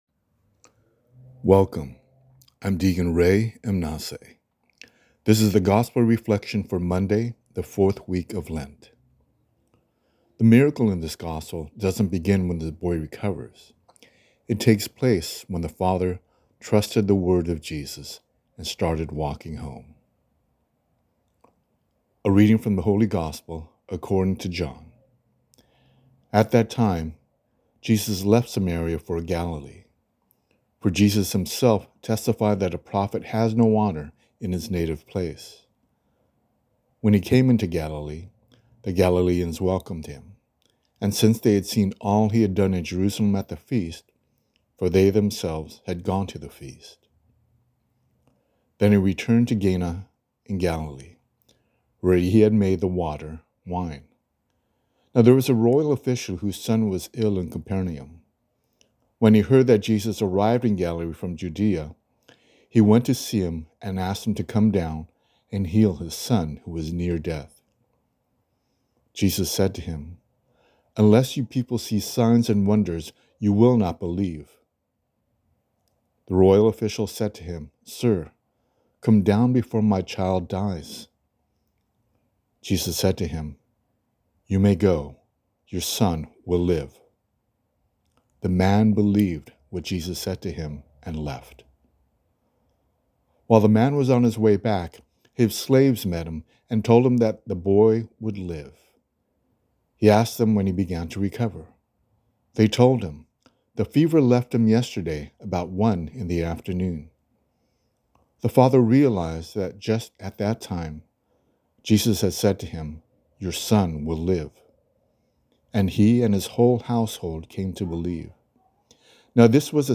A Daily Gospel Reflection